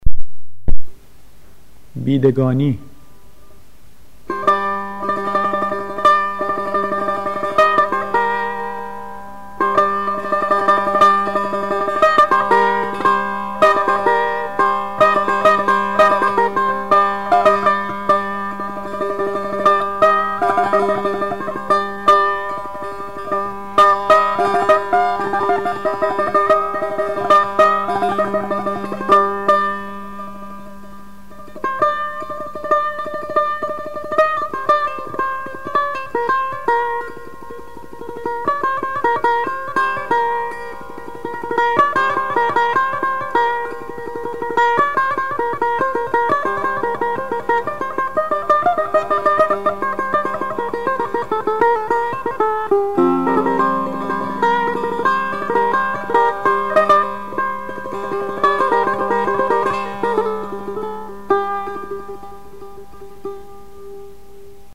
آواز دشتی ردیف میرزا عبدالله سه تار
بیدگانی، آواز دشتی